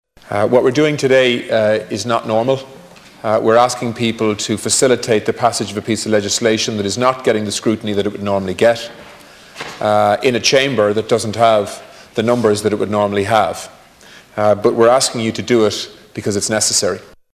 Tánaiste Simon Coveney says the government will do all it can to support people: